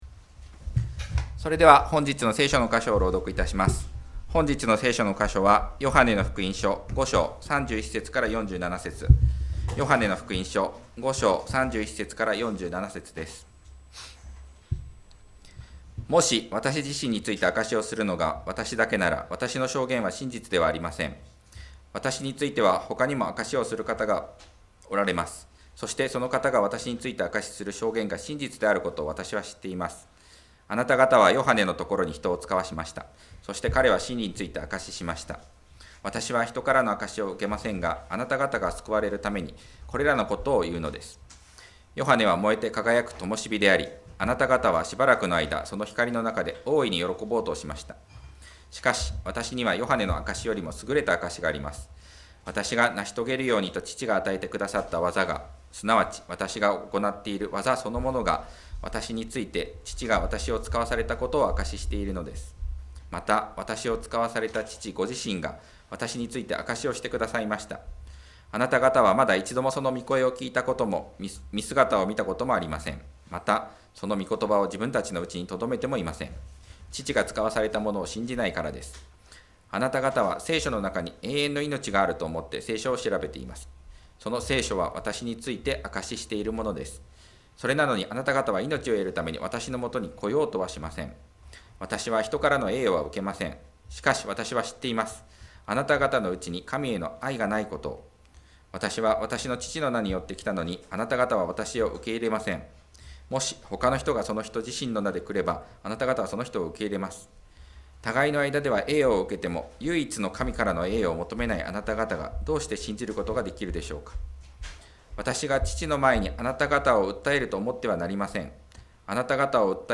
礼拝式順
For English sermon summaries or other support to participate, please contact us.